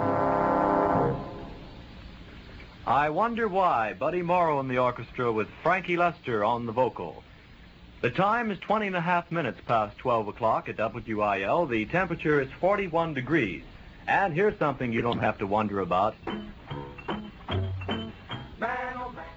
WIL Unknown Announcer aircheck · St. Louis Media History Archive
aircheck